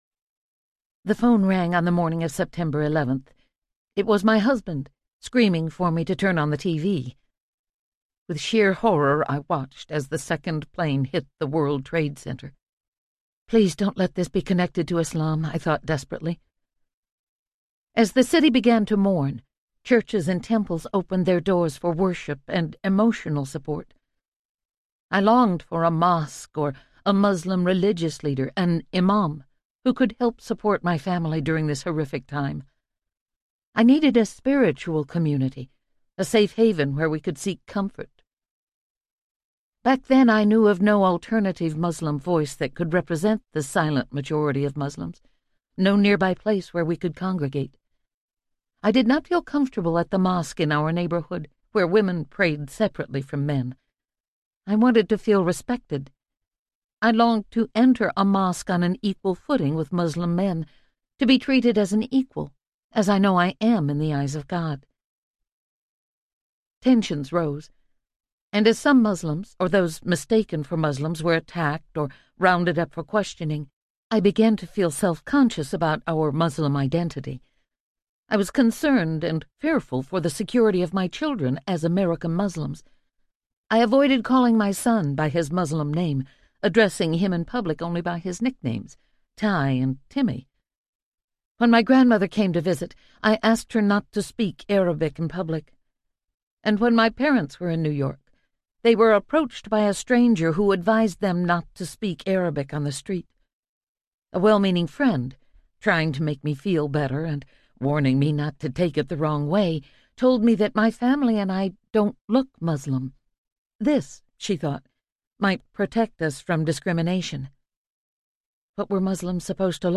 The Faith Club Audiobook
Narrator
11.5 Hrs. – Unabridged